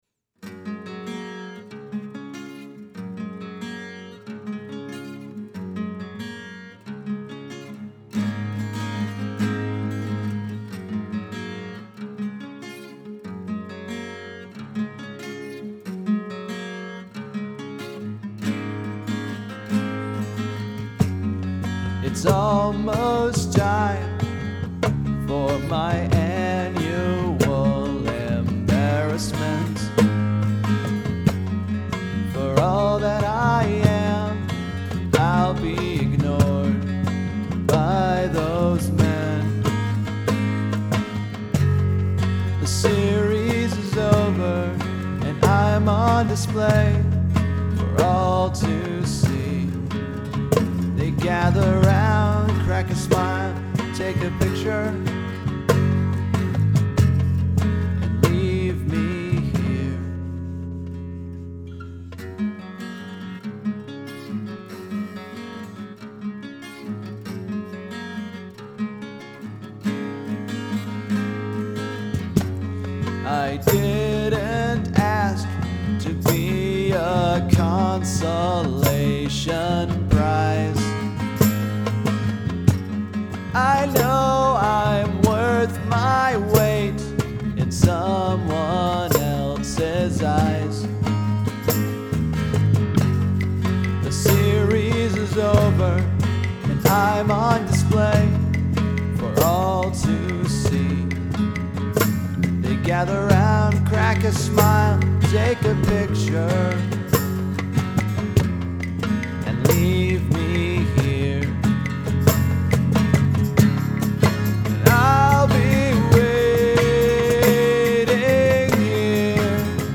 So what better time to go acoustic then right now? For you inquisitive guitar dudes, this song is in Drop-D-flat tuning (Db Ab Db Gb Bb Eb, or C# G# C# F# A# D# on your tuner). I also enjoyed adding all the percussion – this song features cajon, darbuka, tambourine, and claves.